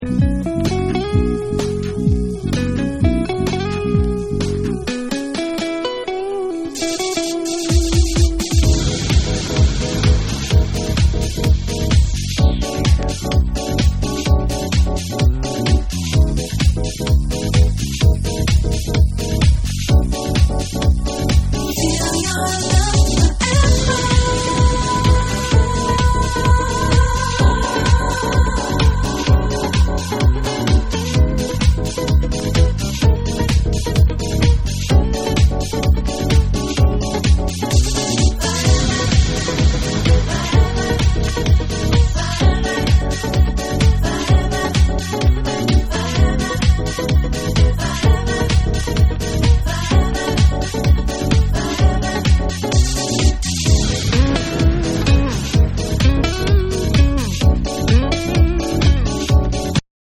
Live Version